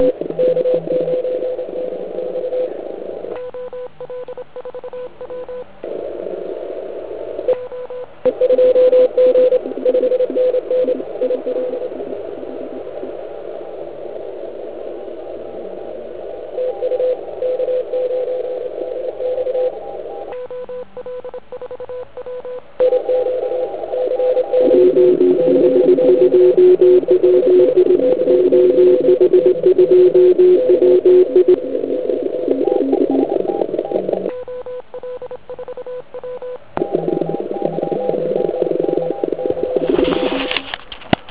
Band je klasicky "panelákově zaprskán asi na S9.